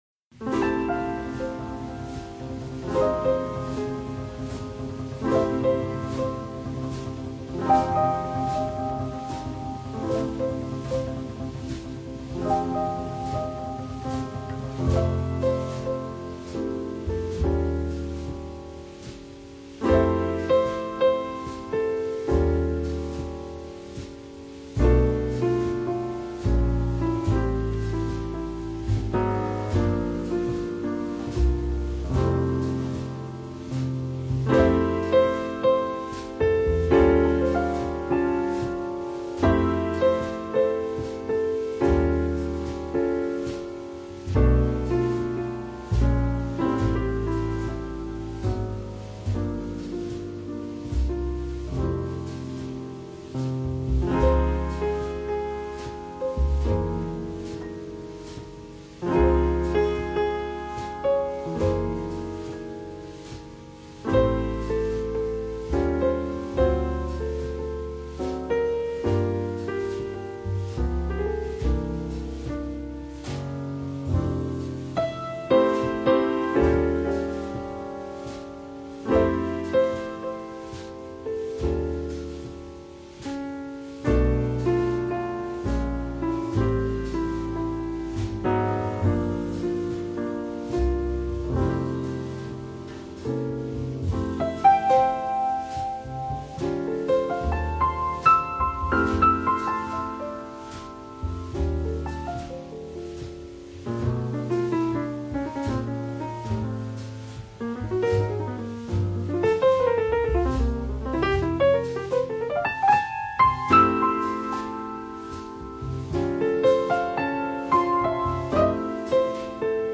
It’s an old tune, one that he learned from his old mentor.
It’s a calming, almost rejuvenating song, with a slow, simple melody played at a relaxed pace. It tip-toes between happy and melancholy- Above all else, it’s wistful, and captures the wonder of a snowy winter’s night, with the holiday season on the mind.